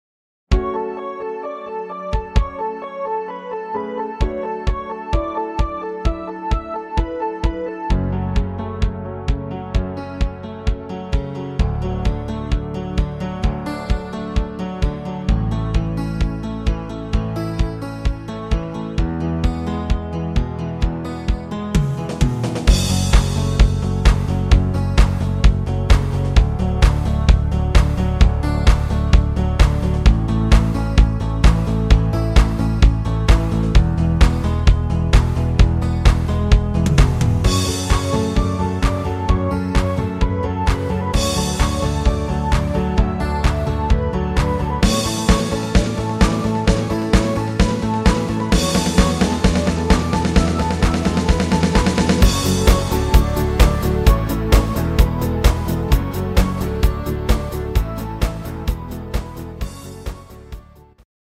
Transpose minus 5